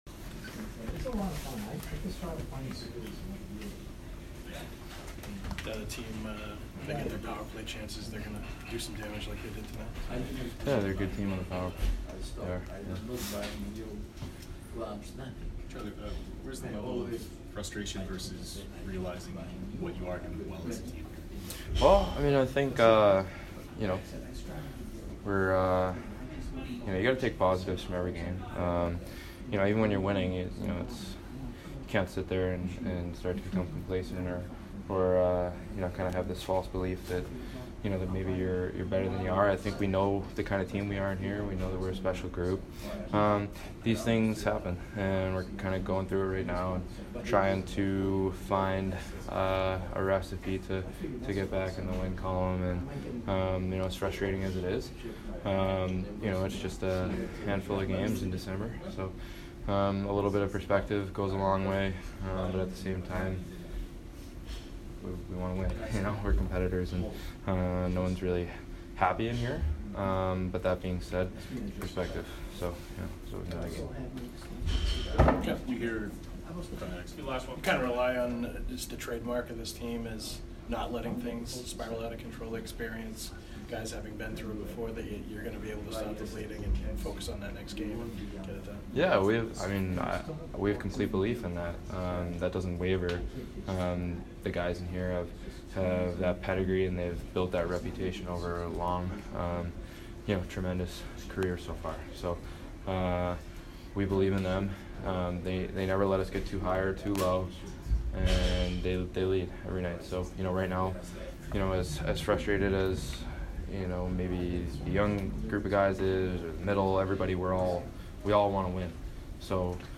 Mcavoy post-game 12/12